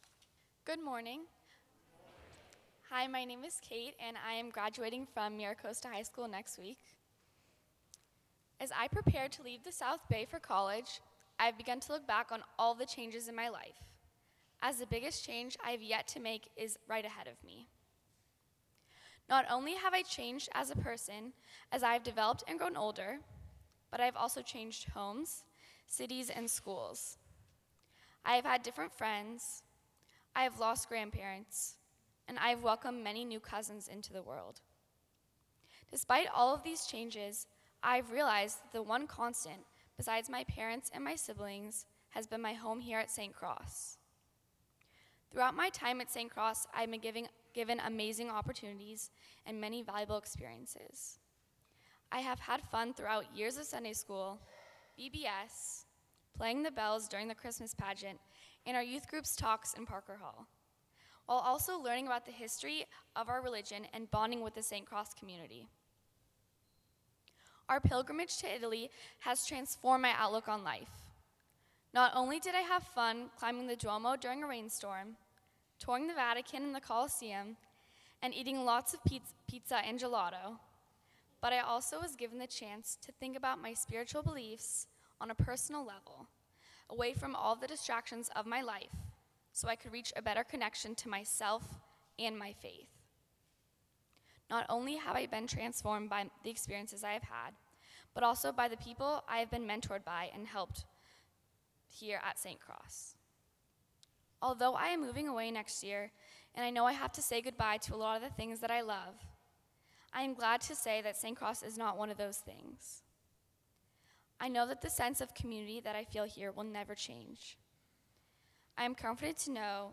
Sermons from St. Cross Episcopal Church
Sermons given by two of our high school graduates